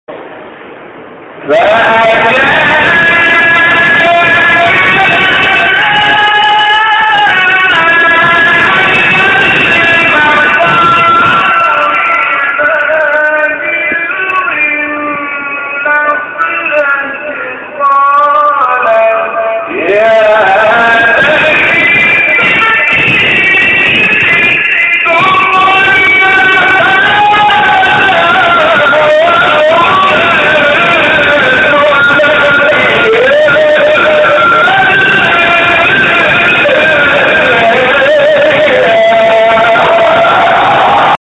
شبکه اجتماعی: مقاطع صوتی از قاریان ممتاز کشور را می‌شنوید.
سوره مریم در افغانستان